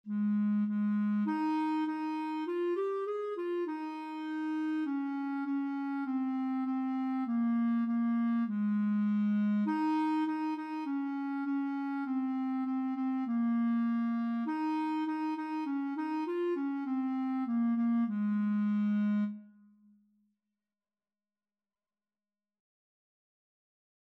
Traditional Trad. Baa, Baa Black Sheep Clarinet version
Moderato
4/4 (View more 4/4 Music)
Ab4-Ab5
Ab major (Sounding Pitch) Bb major (Clarinet in Bb) (View more Ab major Music for Clarinet )
Clarinet  (View more Beginners Clarinet Music)
Traditional (View more Traditional Clarinet Music)